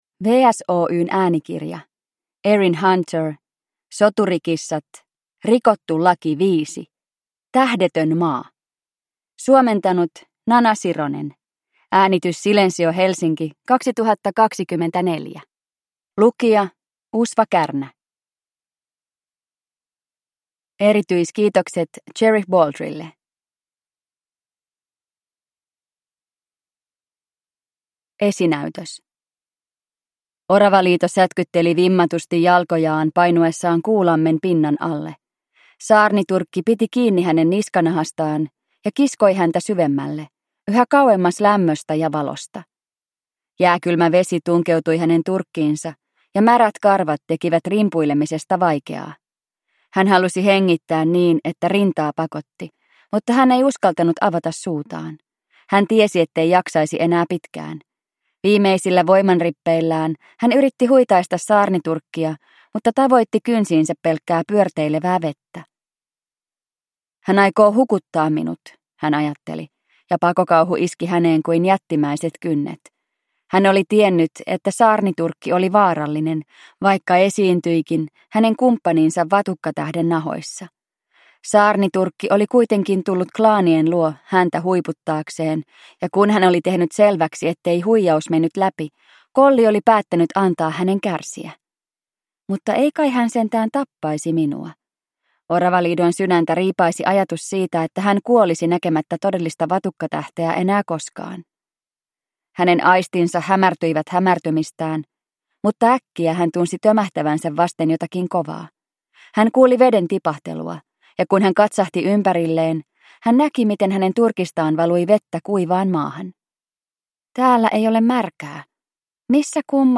Soturikissat: Rikottu laki 5: Tähdetön Maa – Ljudbok